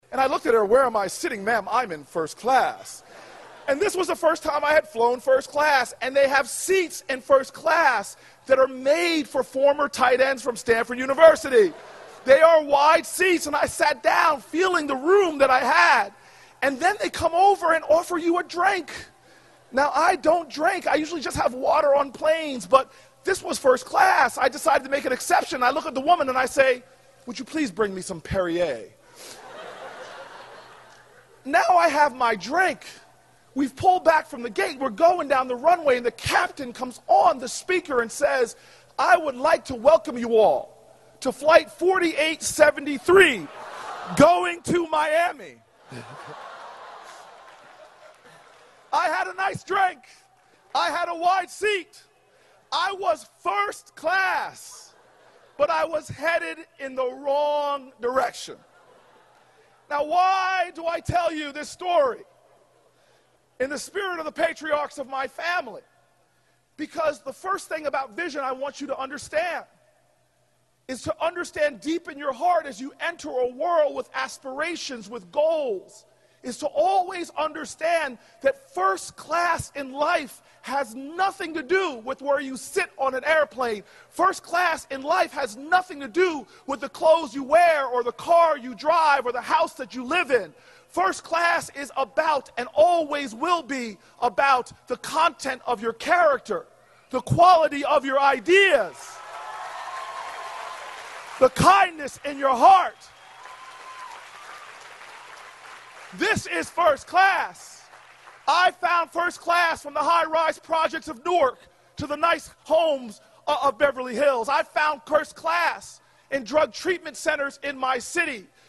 公众人物毕业演讲第443期:科里布克2013年耶鲁大学(11) 听力文件下载—在线英语听力室